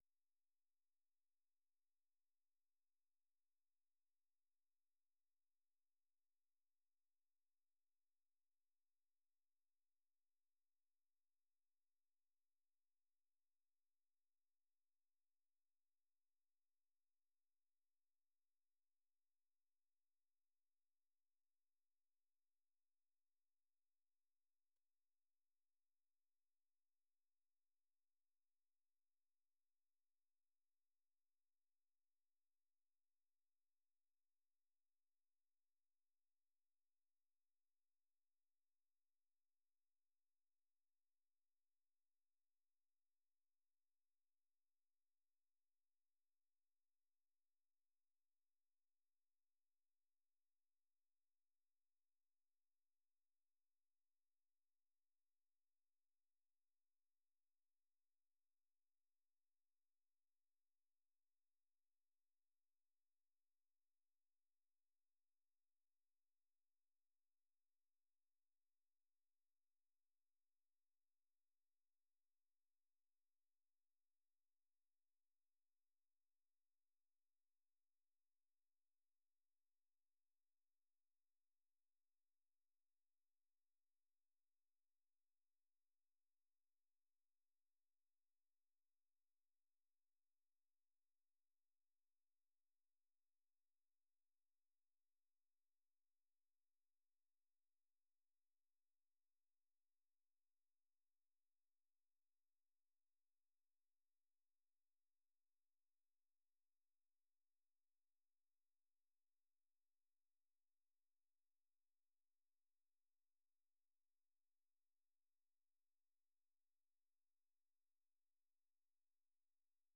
VOA 한국어 방송의 일요일 오후 프로그램 2부입니다. 한반도 시간 오후 9:00 부터 10:00 까지 방송됩니다.